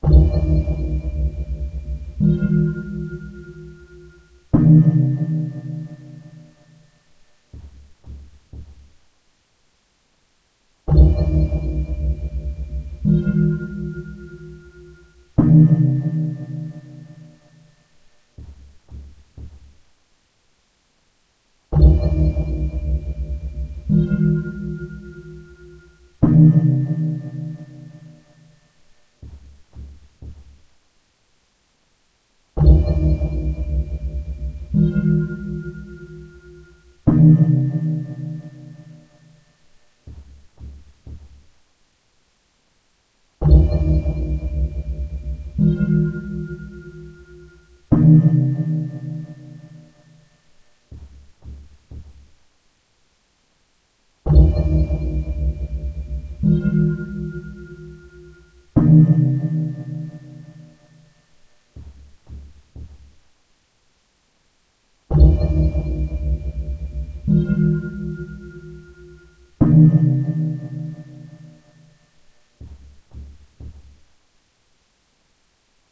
Speed 120%